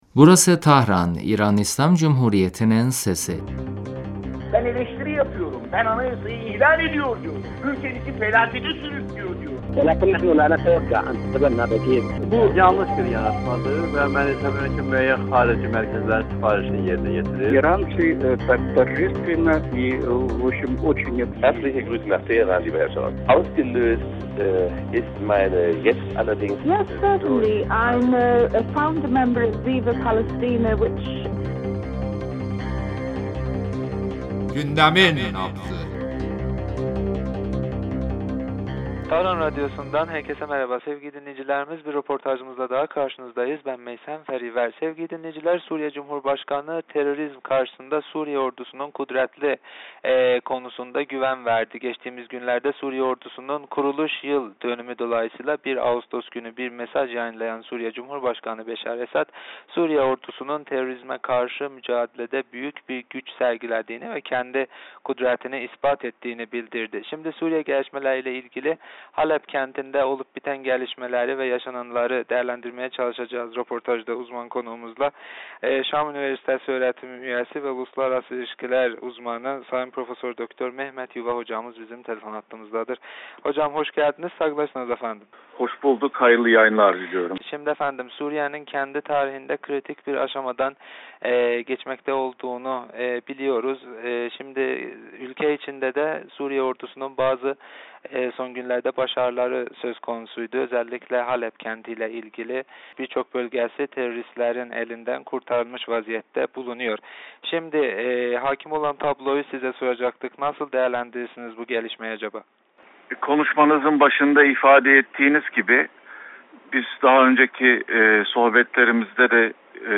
radyomuza verdiği demecinde Suriye'nin Halep kentinde yaşanan gelişmeleri değerlendirdi.